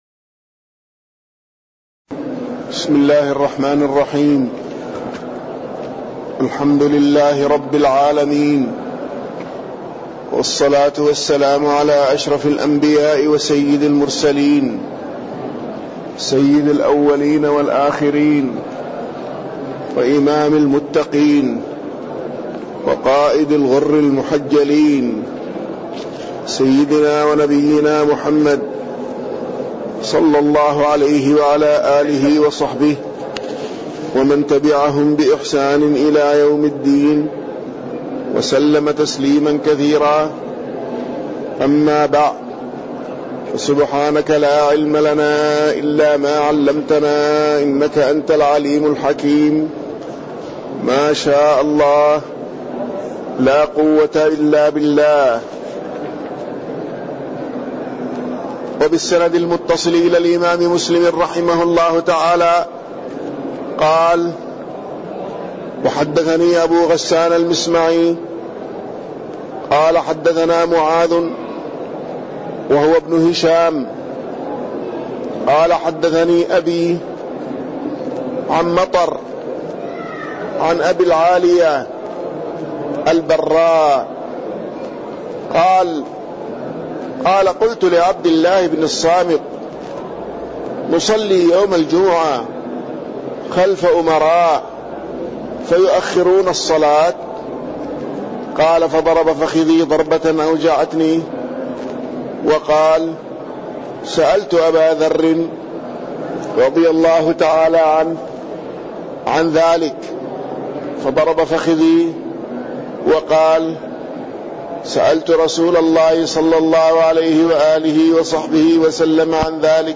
تاريخ النشر ٢٧ صفر ١٤٣٠ هـ المكان: المسجد النبوي الشيخ